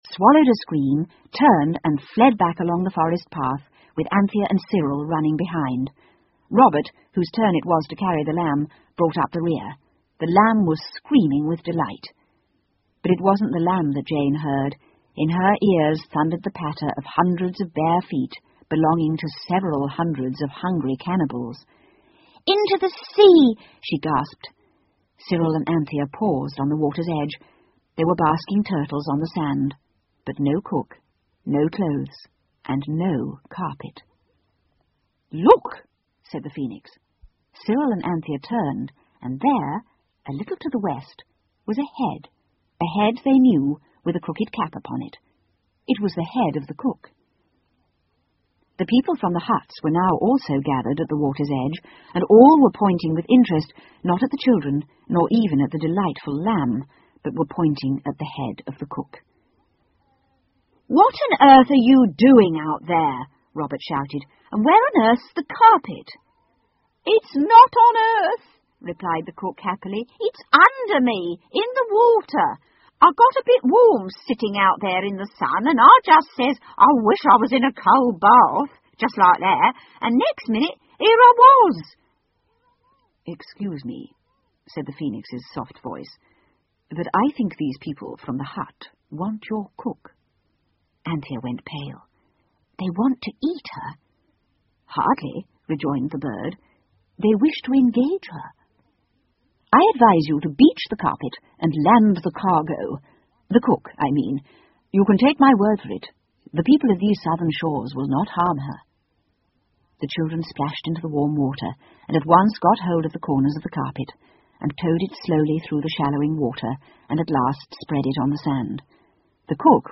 凤凰与魔毯 The Phoenix and the Carpet 儿童英语广播剧 6 听力文件下载—在线英语听力室